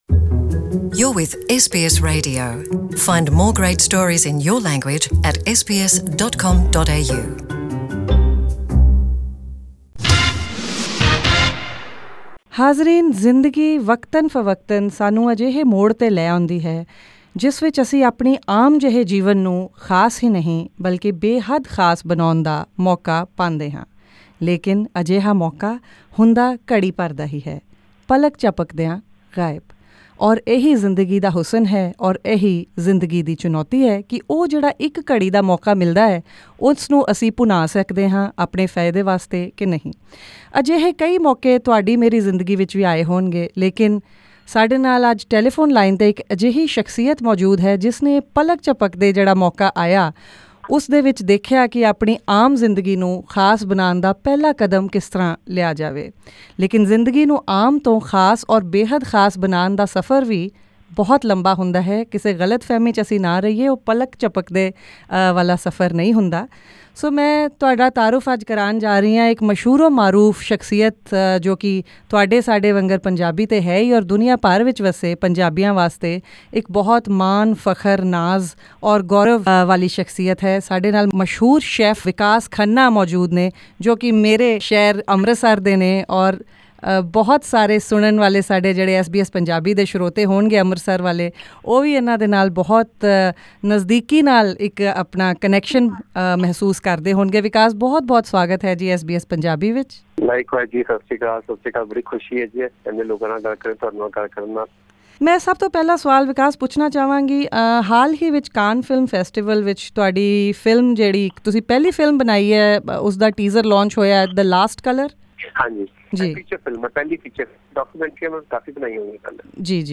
SBS Punjabi caught up with Mr Khanna shortly after he launched the teaser of his upcoming feature film, The Last Color , featuring famous Indian actress, Neena Gupta, at the 71 st Cannes Film Festival.